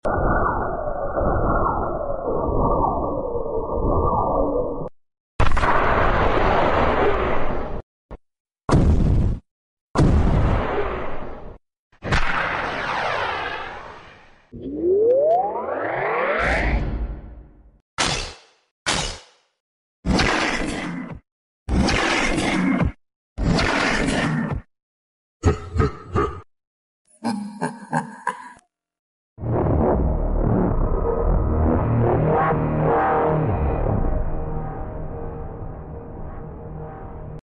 แจกๆ Astro Toilet Sounds ของฟรีก็รับไปซะ!!🤑🤑🤑 Sound Effects Free Download